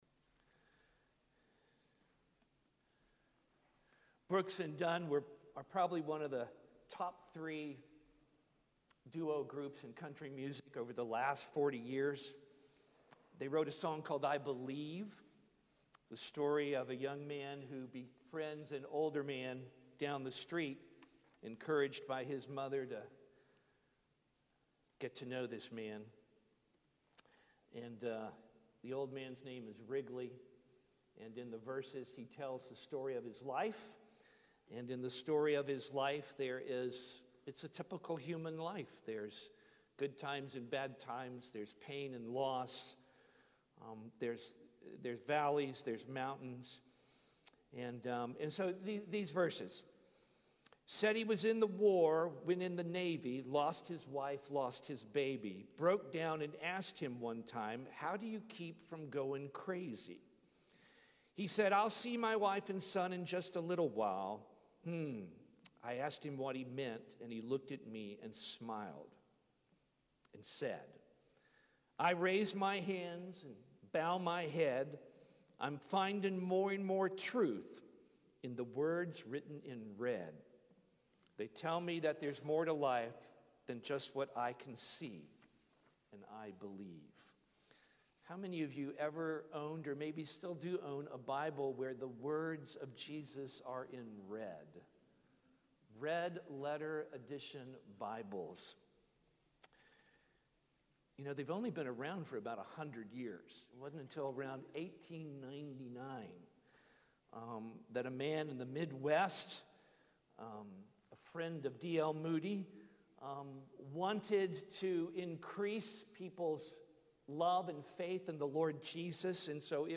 Sermon 1/1/2023 The Second Sunday of Christmas